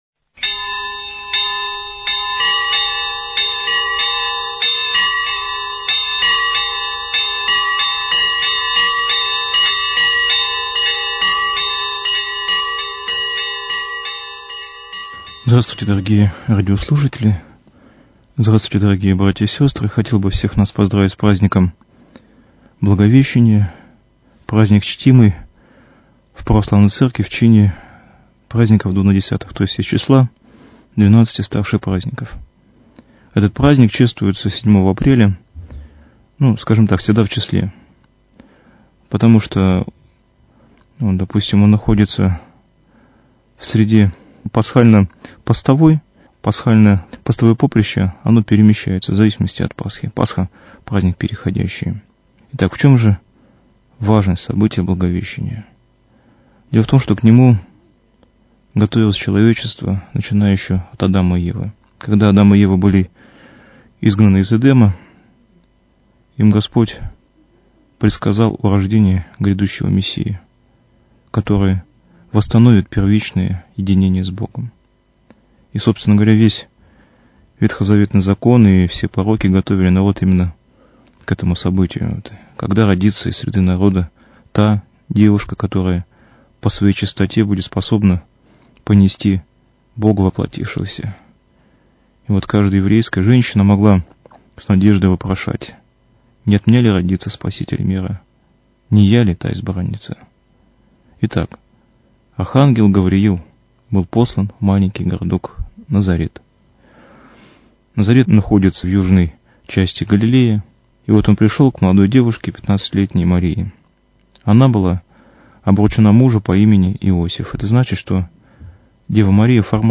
Ведущий священник